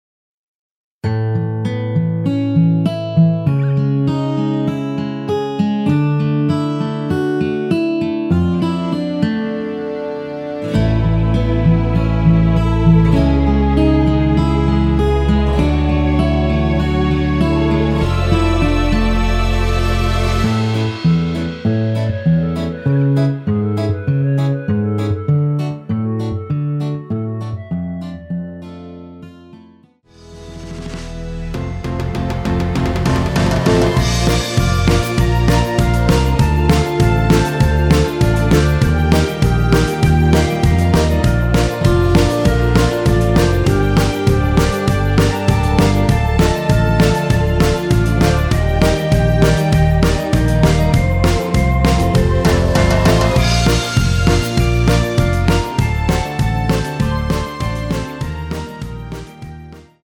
원키에서(-2)내린 멜로디 포함된 MR입니다.
앞부분30초, 뒷부분30초씩 편집해서 올려 드리고 있습니다.